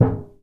metal_tin_impacts_deep_01.wav